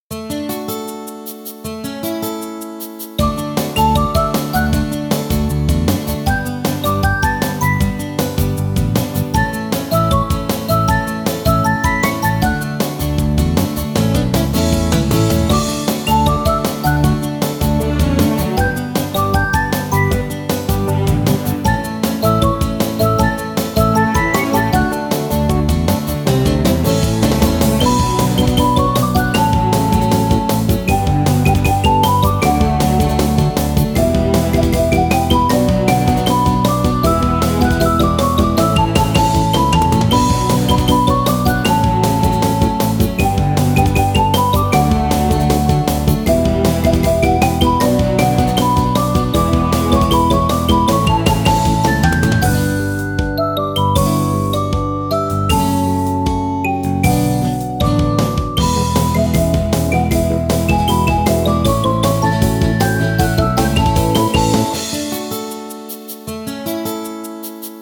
ogg(L) 街 爽快 明るい アップテンポ
軽快なギターとドラムに楽しげなメロディを乗せたポップな曲。